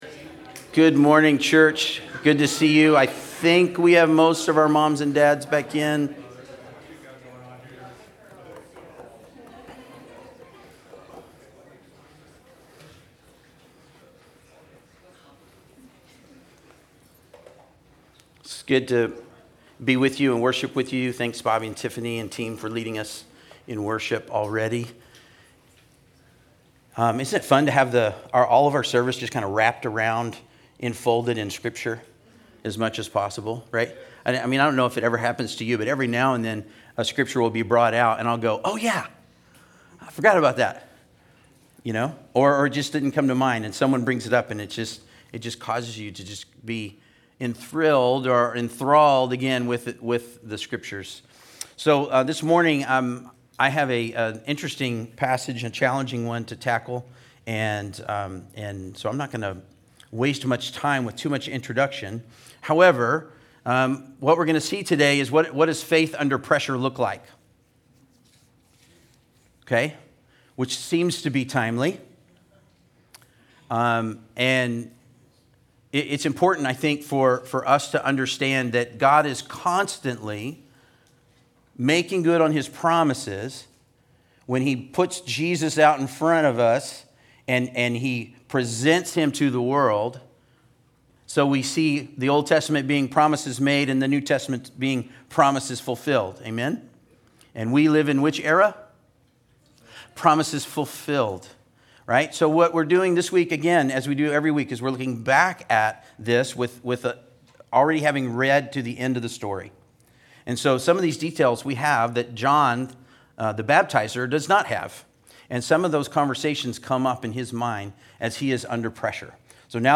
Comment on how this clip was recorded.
Luke 7:18-35 Service Type: Sunday Service Related « Lord?